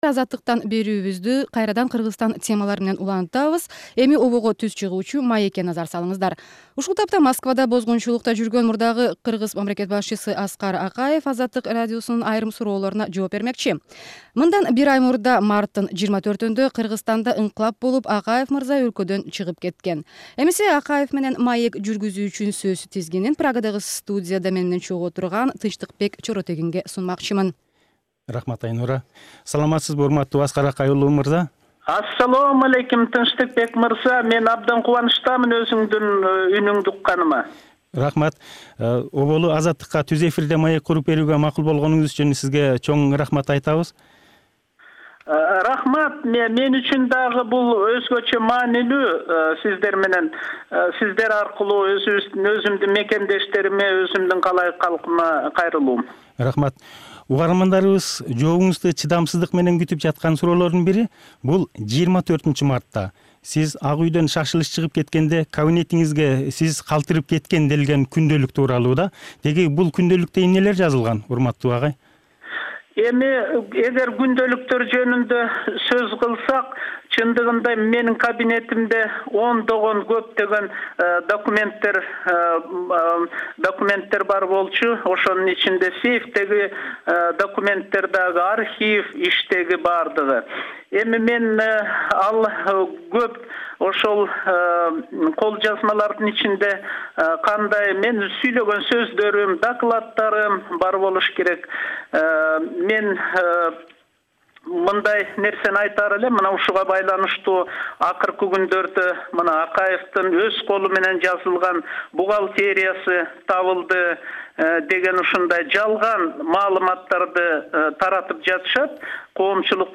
Аскар Акаев менен маек_1-бөлүк_2005-ж., 24-апрел_ТЧ